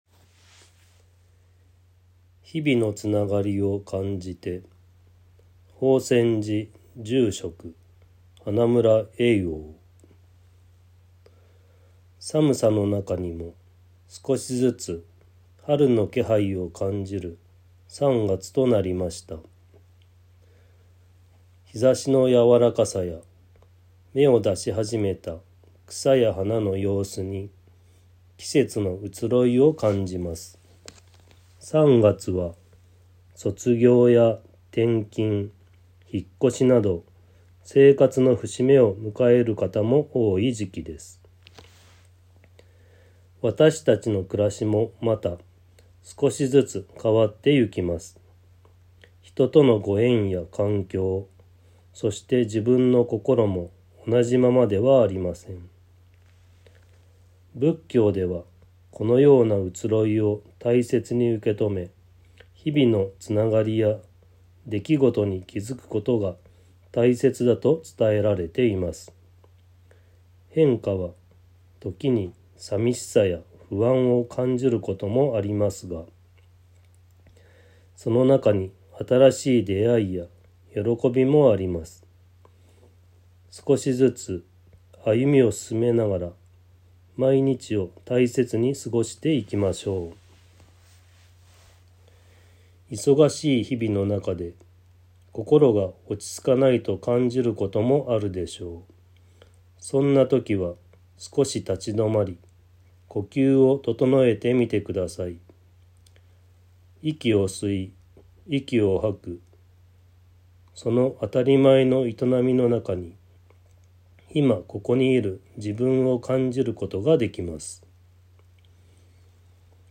曹洞宗岐阜県宗務所 > テレフォン法話 > 「日々のつながりを感じて」